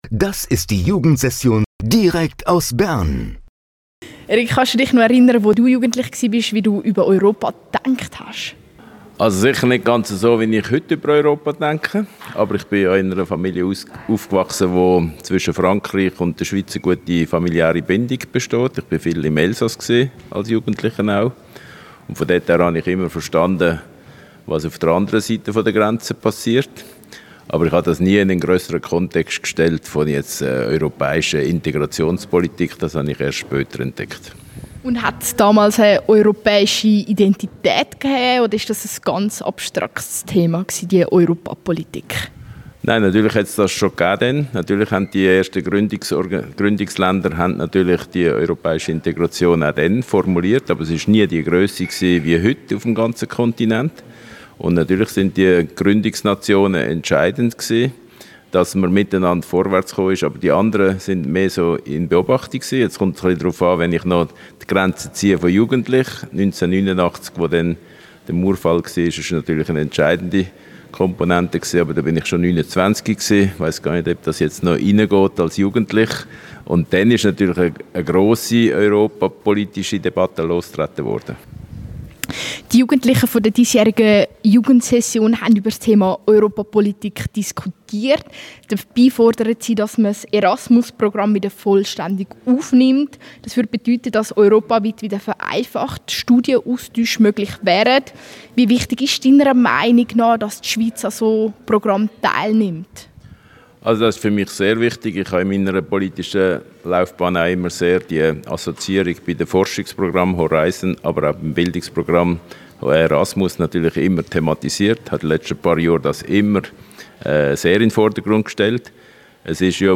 Im Rahmen der Jugendsession 2024, erzählt er uns, wie er als Jugendlicher über Europa gedacht hat und wie sich Europa in den letzten Jahren gewandelt hat. Auch erklärt er, wieso die Wiederaufnahme von Erasmus+ trotz kritischen Stimmen wichtig ist.
Wie die Beziehung zwischen der Schweiz und der EU in 20 bis 30 Jahren aussehen könnte, verrät er im Radiobeitrag.